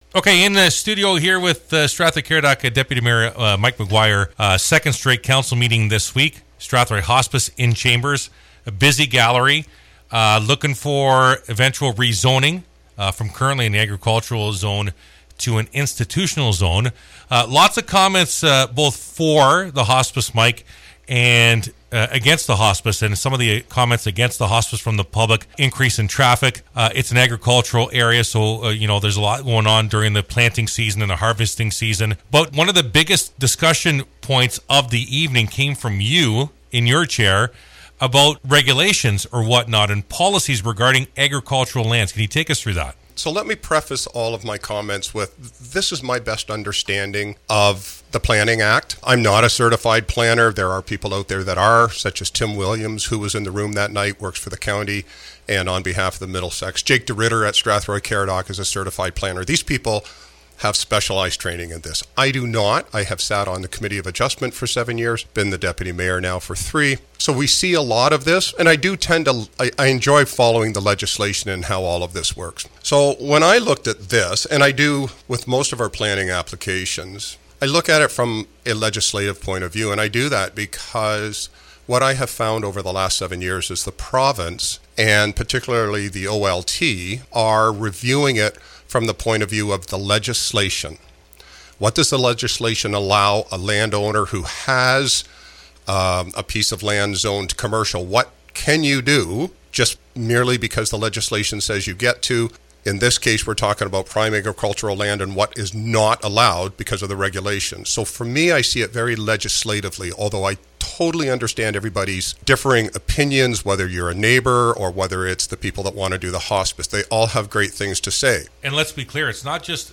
Hospice in town still the goal but where is still the question – Deputy Mayor McGuire goes 1 on 1 about the issues